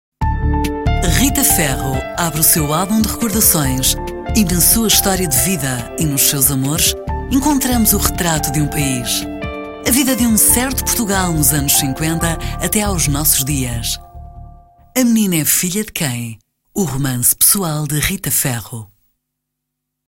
电影片花